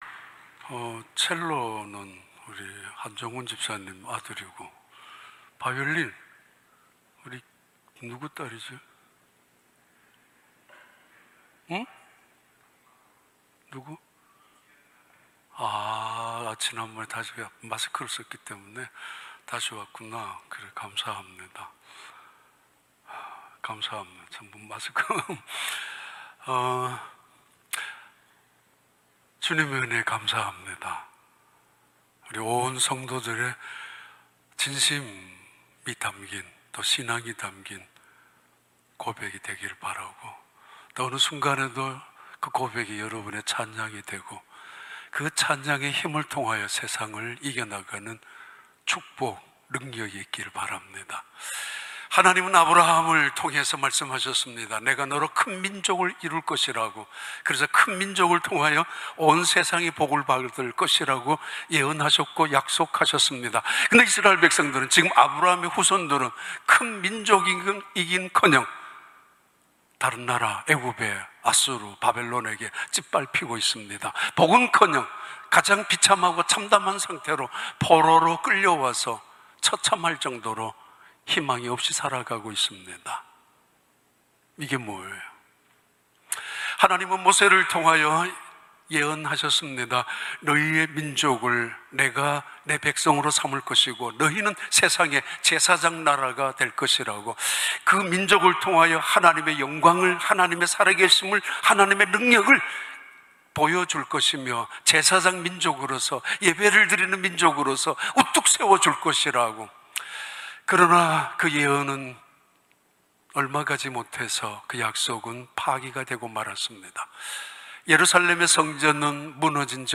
2021년 9월 5일 주일 3부 예배